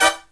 HIGH HIT08-R.wav